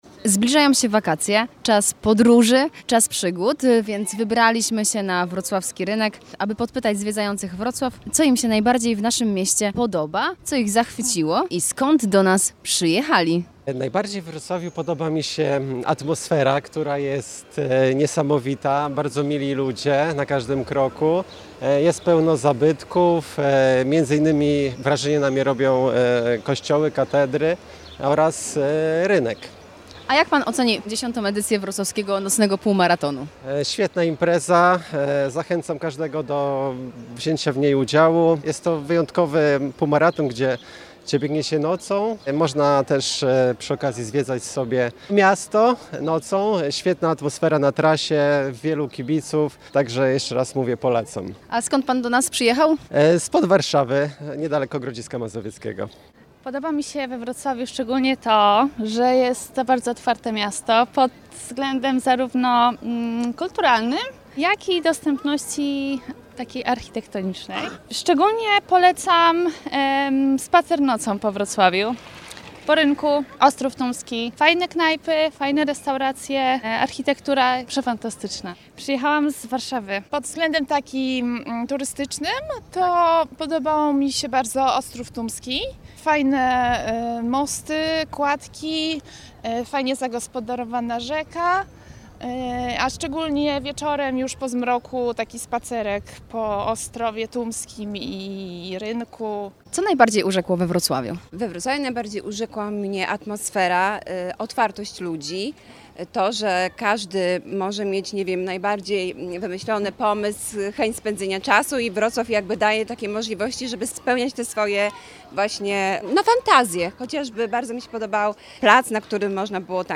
Sonda-wroclaw-oczami-turystow.mp3